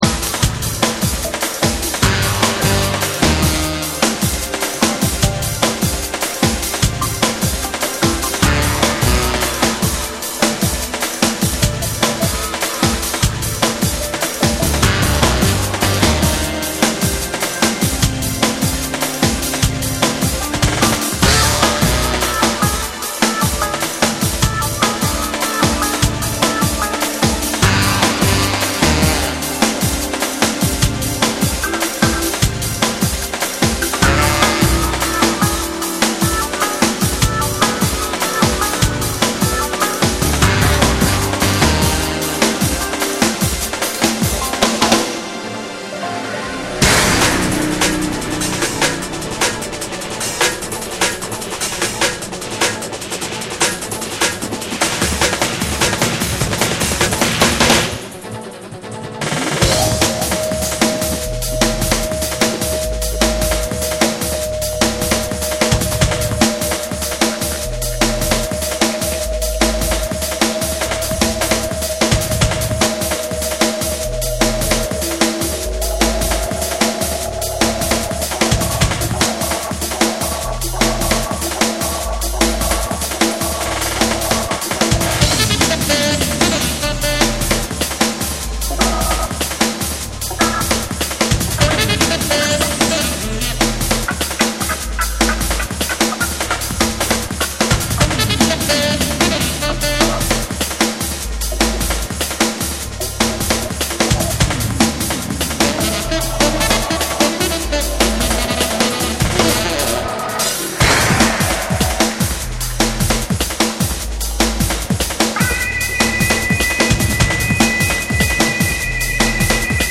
ジャジーなサックスをフィーチャーした高速ブレイクビーツ
BREAKBEATS / JUNGLE & DRUM'N BASS